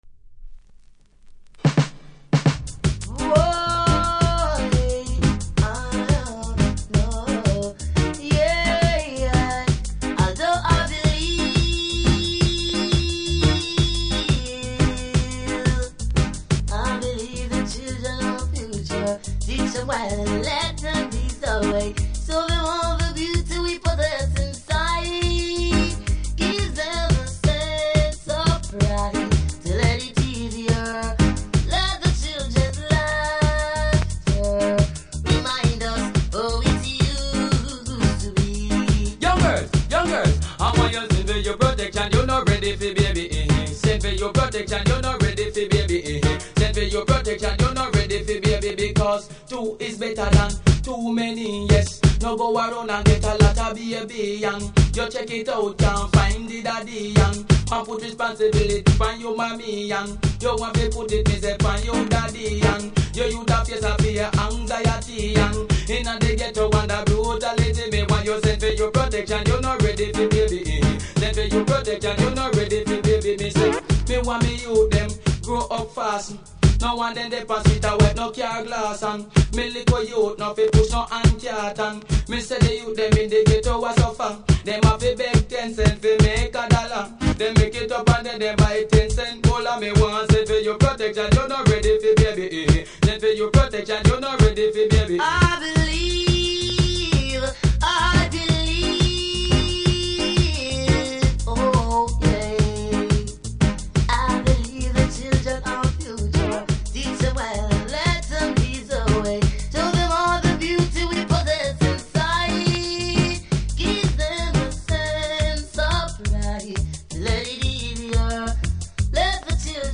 REGGAE 80'S
中盤何発かノイズありますので試聴で確認下さい。